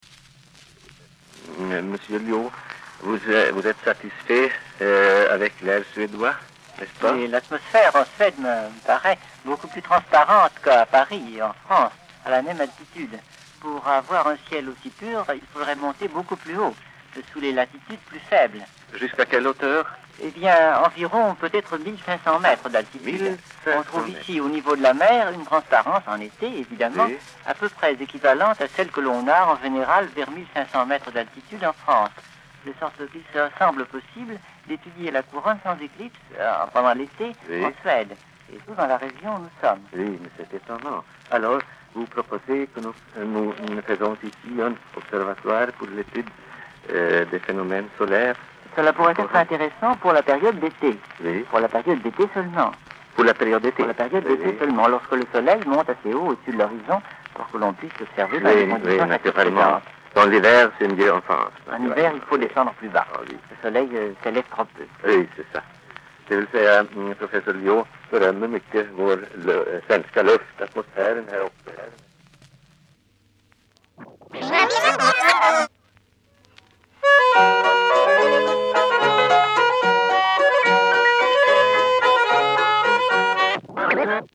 Interview de Bernard Lyot, 1945
Collection : Archives orales